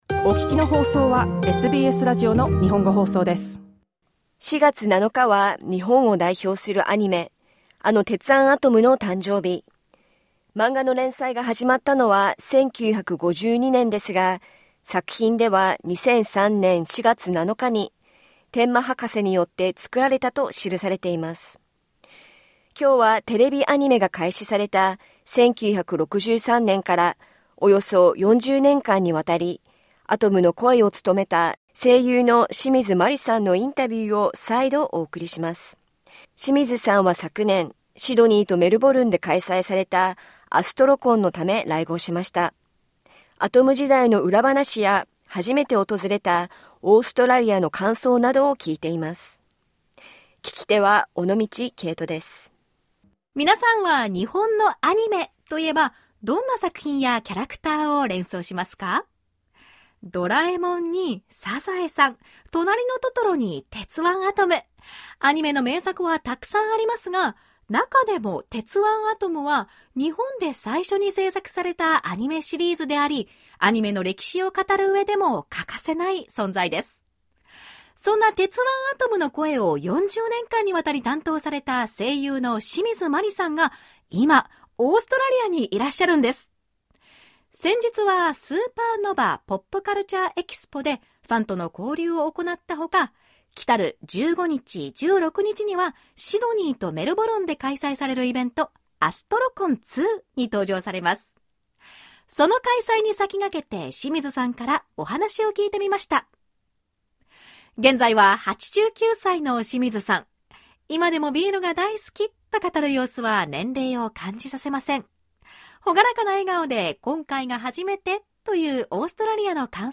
To mark the occasion, we revisit our interview with legendary voice actress Mari Shimizu, who brought Astro Boy to life for nearly four decades, beginning with the TV anime in 1963.